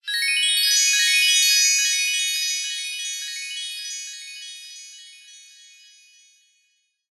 Звуки феи
Волшебная феерическая пыль, сверкающий блестящий блистательный звон деревянных колоколов